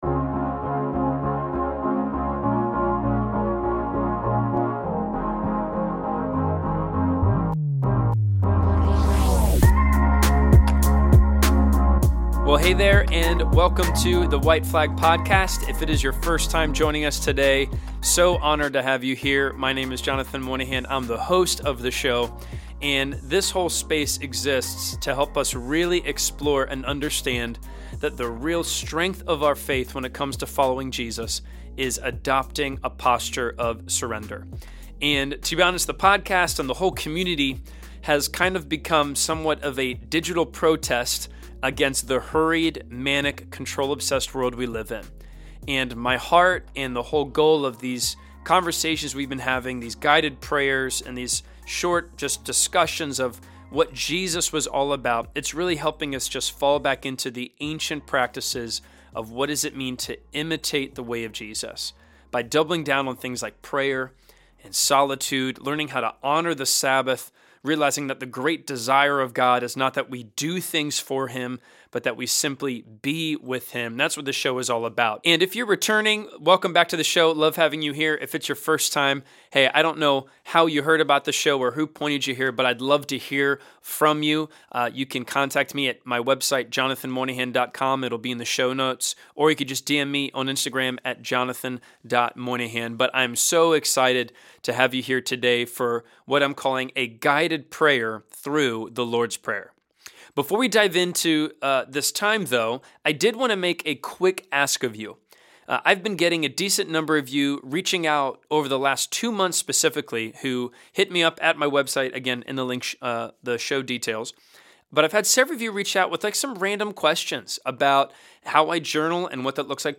This episode is a guided prayer, in hopes of helping you meditate and contemplate your way through the power of The Lord's Prayer.